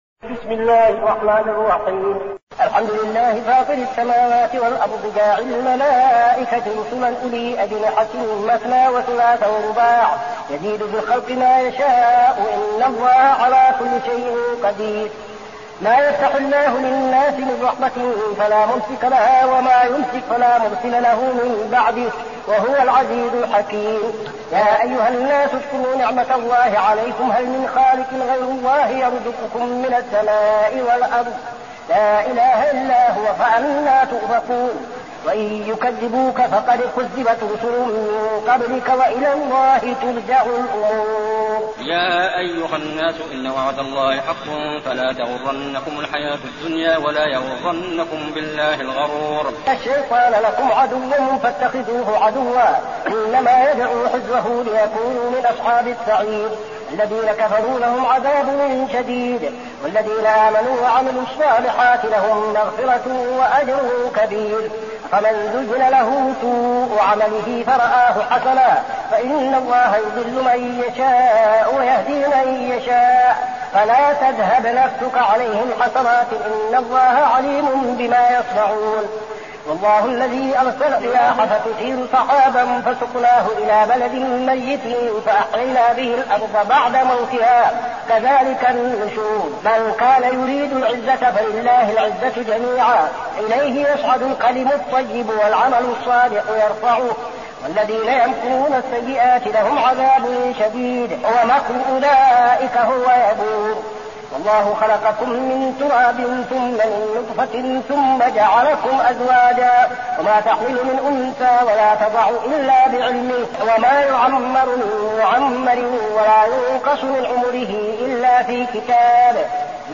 المكان: المسجد النبوي الشيخ: فضيلة الشيخ عبدالعزيز بن صالح فضيلة الشيخ عبدالعزيز بن صالح فاطر